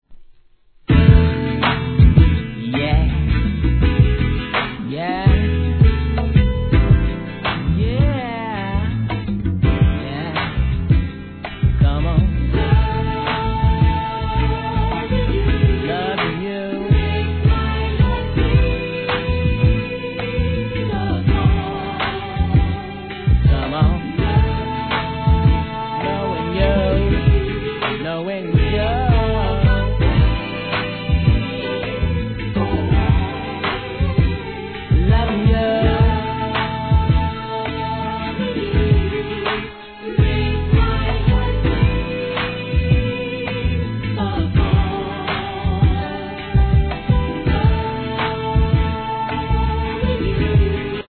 1. HIP HOP/R&B
女の子だけを第一義に作られたスーパー・ラブリー・テンダー6曲いり第二弾!!